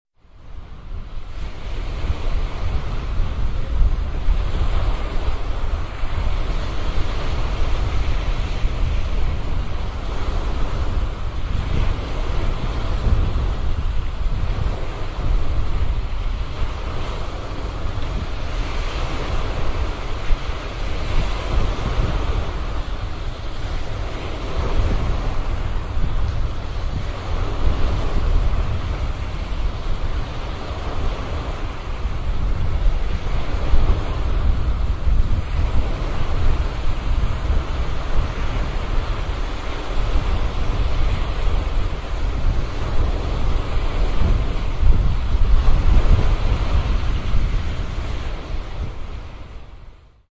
Здесь собраны аудиозаписи, которые раскрывают весь потенциал стереозвука — от успокаивающих мелодий до динамичных эффектов.
Морские волны и ветер